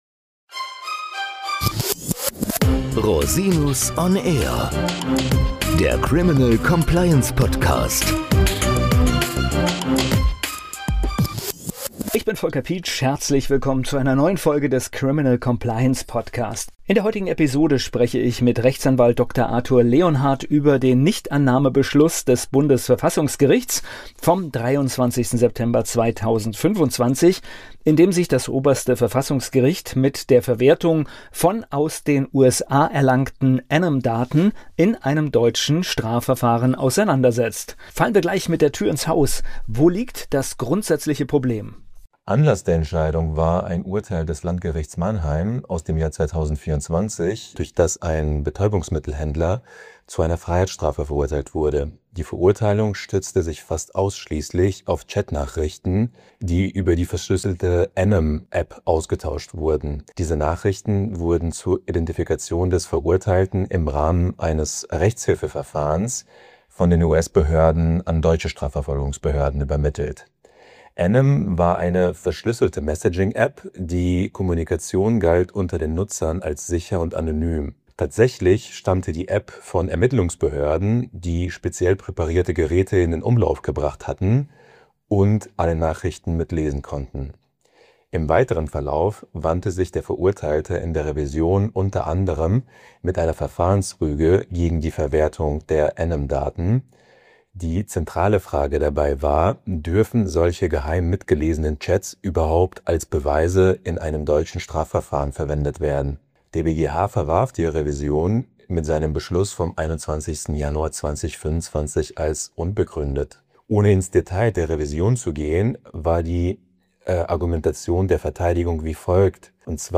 Ein Gespräch über verdeckte Operationen, rechtliche Grauzonen und die Belastbarkeit rechtsstaatlicher Standards.